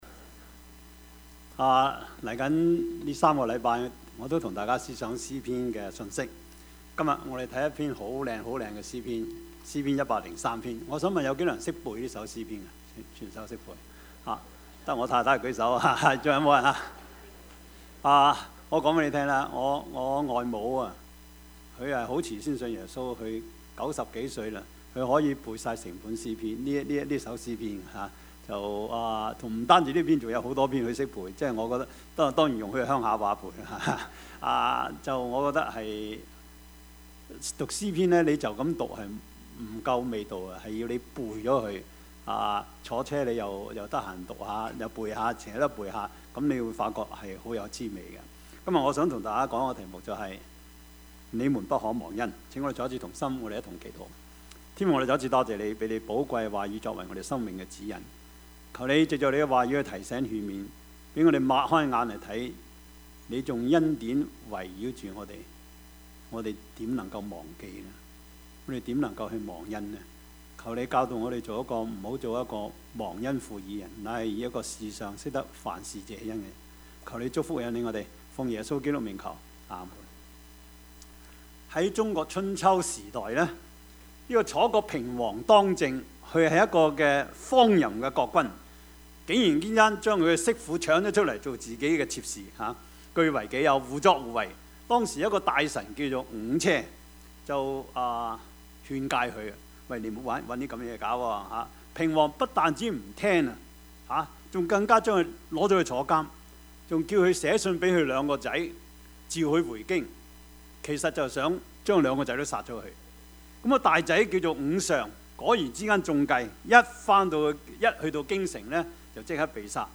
2018 主日崇拜 Passage: 詩 篇 103 Service Type: 主日崇拜 Bible Text
Topics: 主日證道 « 聖經和基督的模範 耶穌的死 »